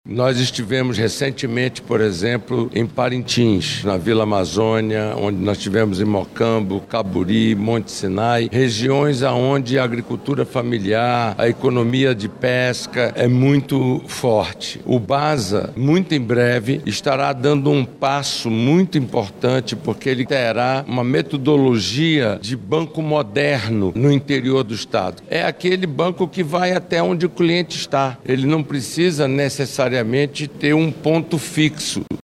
O senador Eduardo Braga, do MDB, esteve na inauguração de uma das agências, em Manaus, e falou da importância da modernização e ampliação do banco no interior do Amazonas.